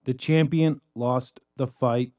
描述：男声样本"冠军失去了斗争quot。
Tag: 120 bpm Cinematic Loops Vocal Loops 379.03 KB wav Key : Unknown